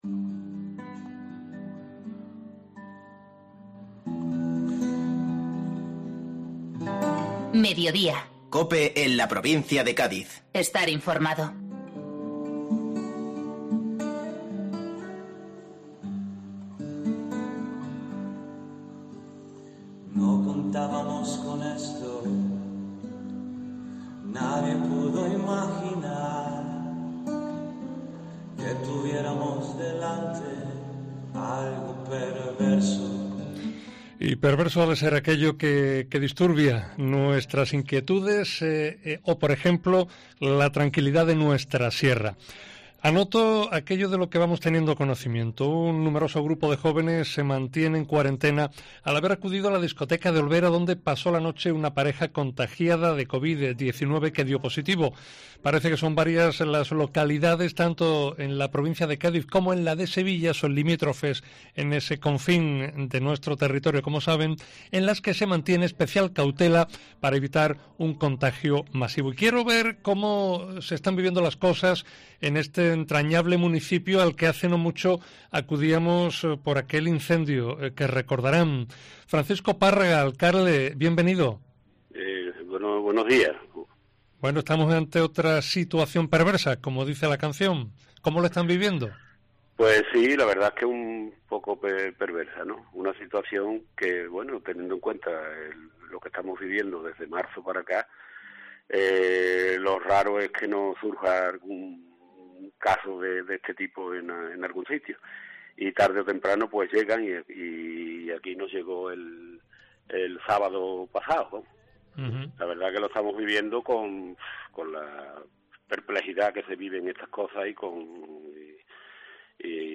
Francisco Párraga, alcalde de Olvera, sobre el brote Covid-10 en su población